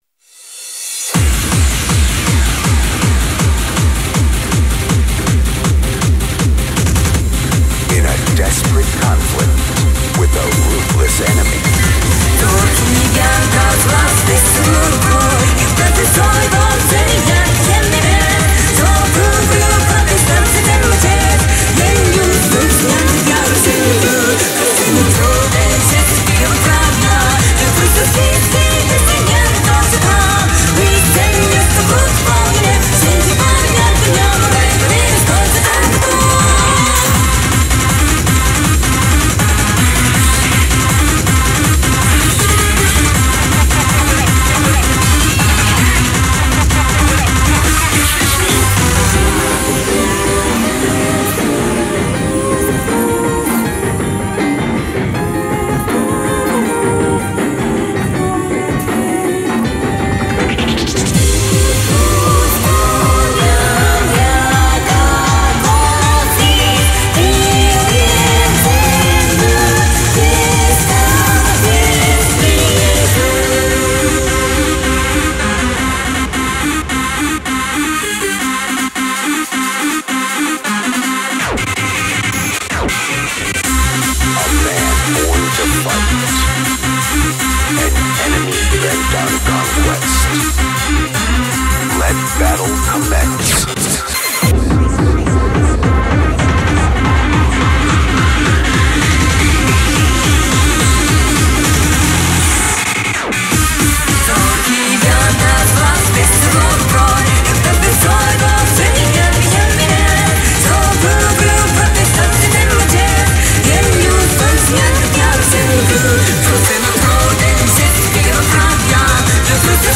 BPM80-160
Genre: HARMONIC SCHRANZ.